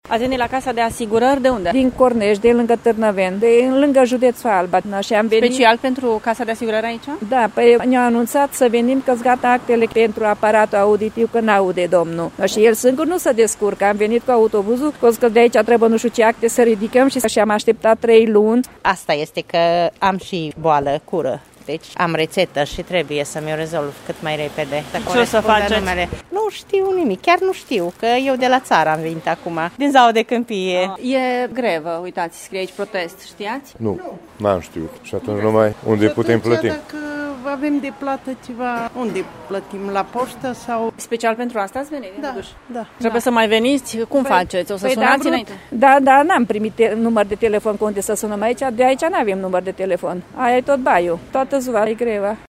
Oamenii au venit de la zeci de km distanță și nu știu ce să facă pentru a-și rezolva problemele medicale pe care le au: